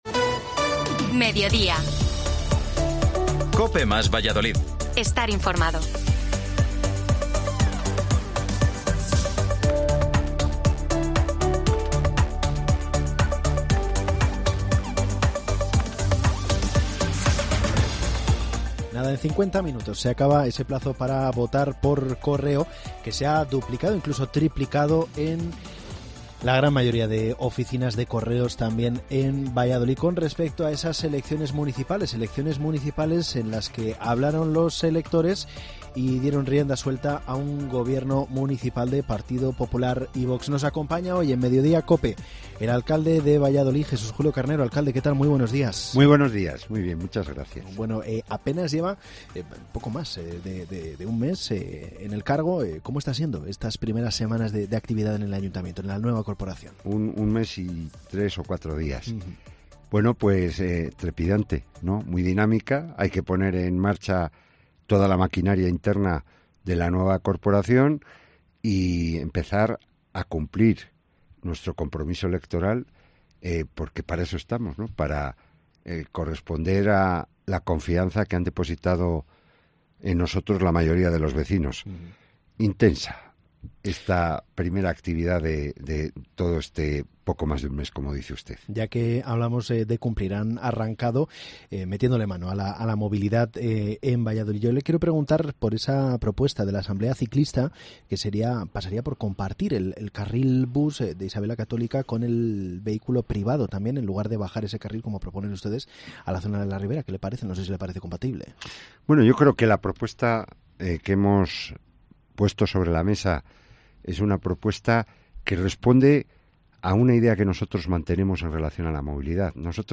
A su paso por el Mediodía en COPE Valladolid , el alcalde de la ciudad ha anunciado en primicia que se pone en marcha la consulta pública previa a la reducción de este espacio público.